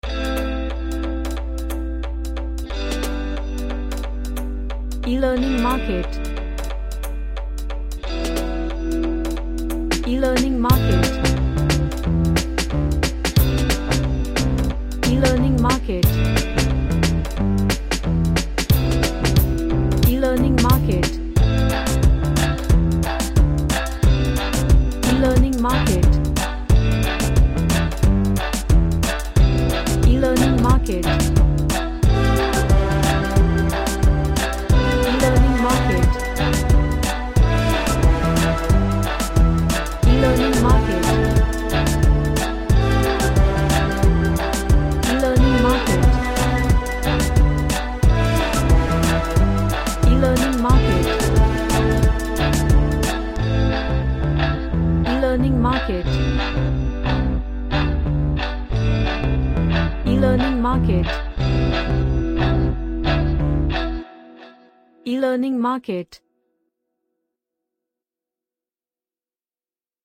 A nice latin song with stereo Brass.
Happy / CheerfulGroovy